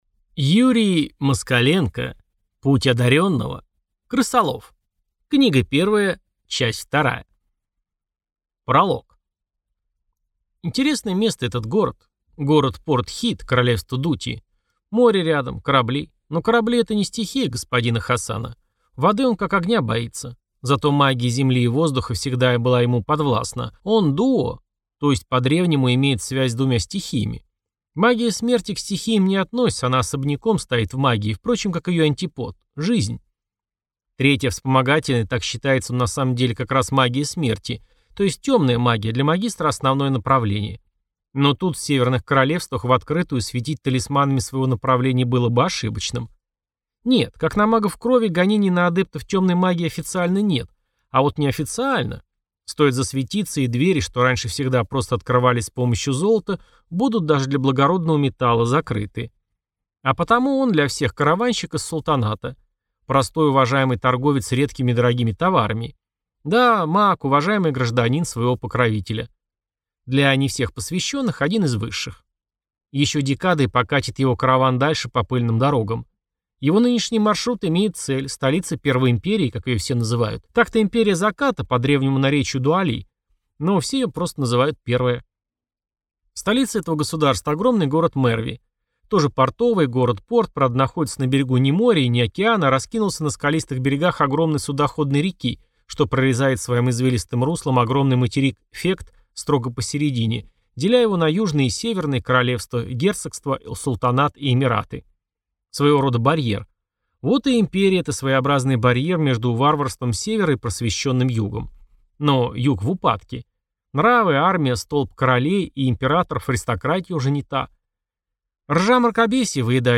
Аудиокнига Путь одарённого. Крысолов. Книга первая. Часть вторая | Библиотека аудиокниг